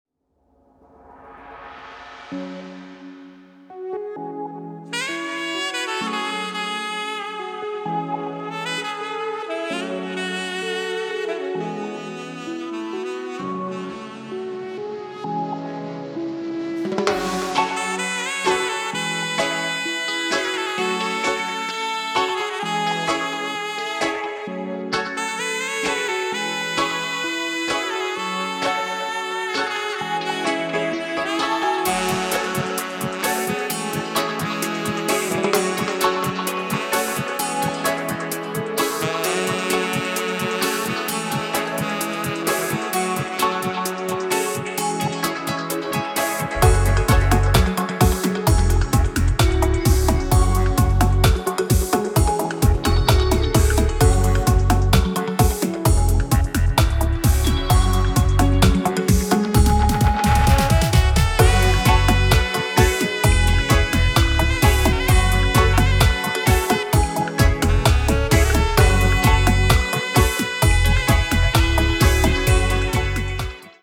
Saxophone